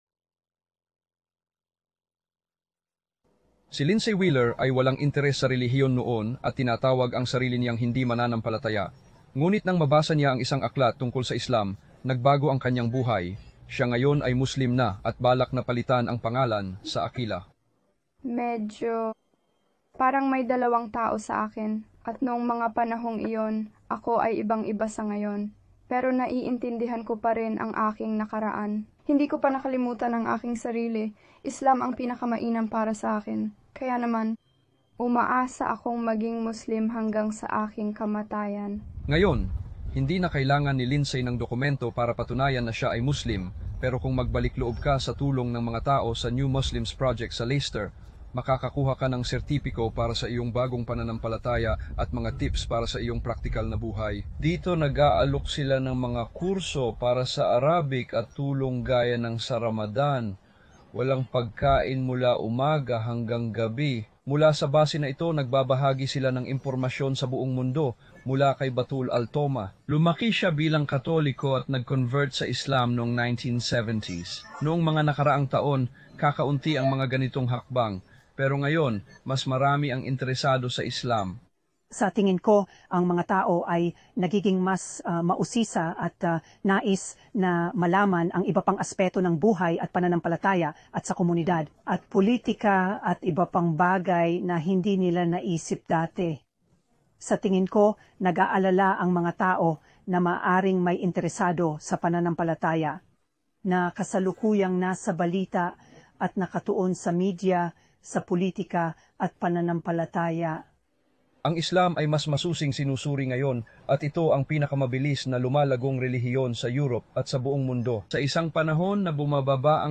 Paglalarawanˇ: Ipinapaliwanag ng balitang ito kung paano naging pinakamabilis lumago ang Islam sa UK at Europa.